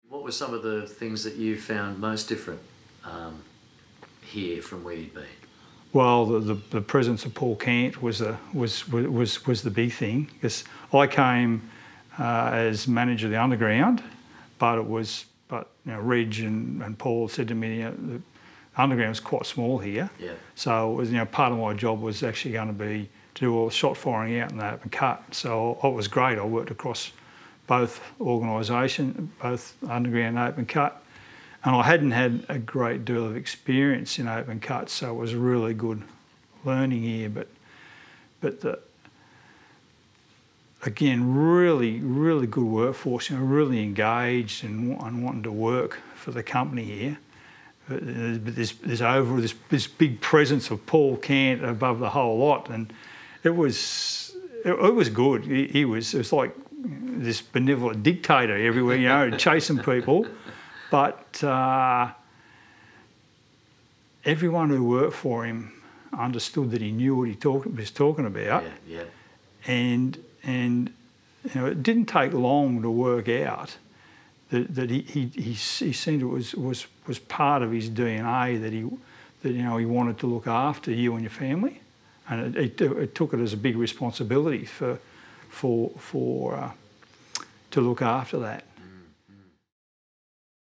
a series of interviews